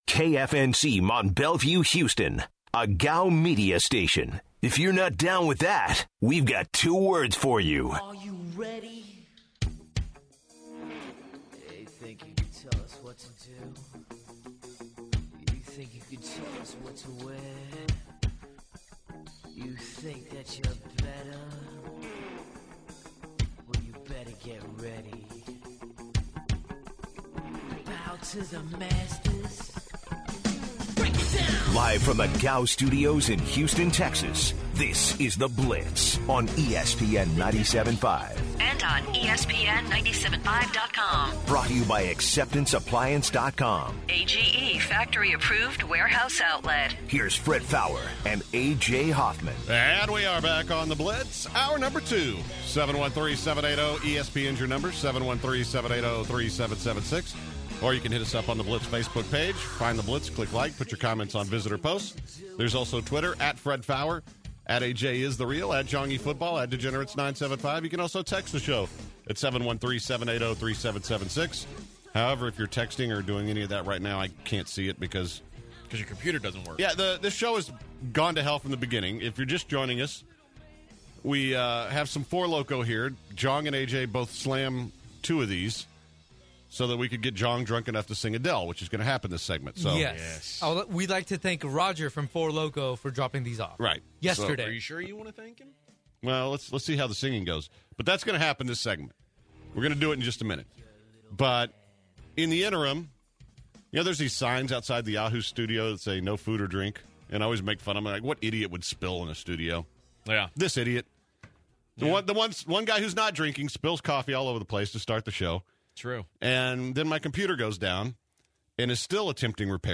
The guys answer your questions.